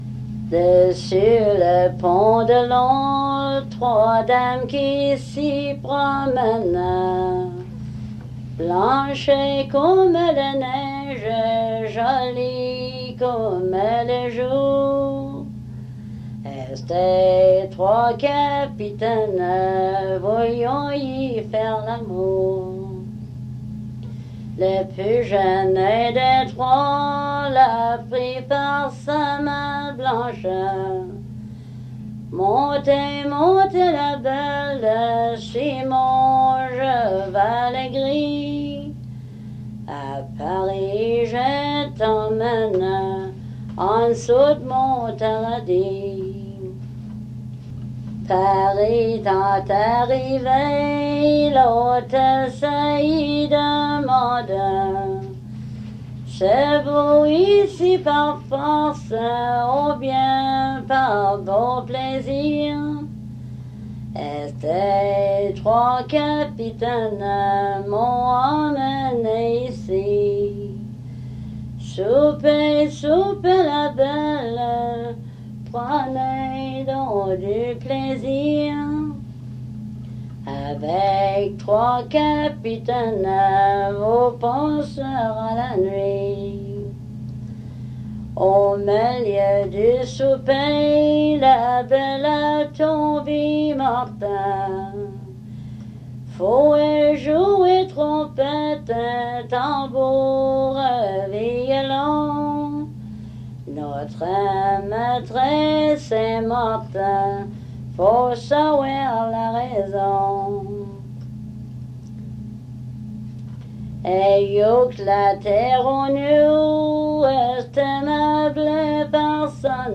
Chanson
Emplacement Cap St-Georges